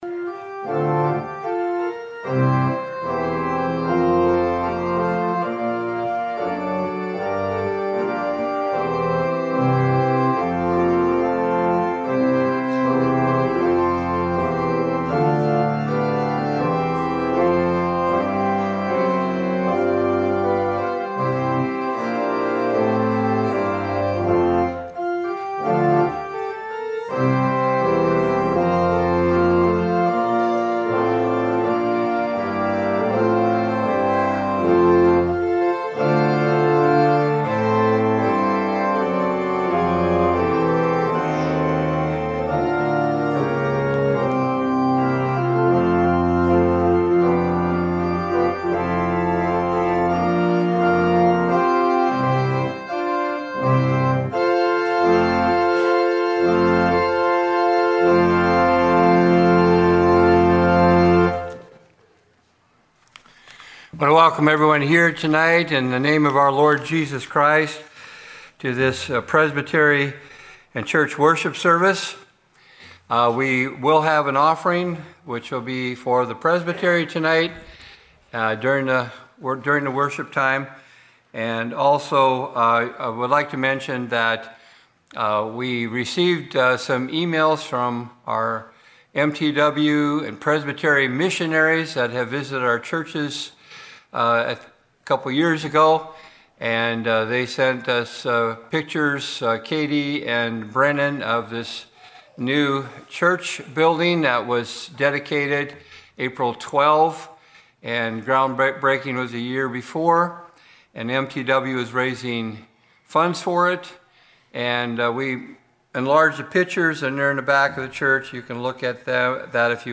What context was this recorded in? Evening worship held April 23, 2026 in the Pollock Memorial Presbyterian Church hosting the Siouxlands Presbytery meeting.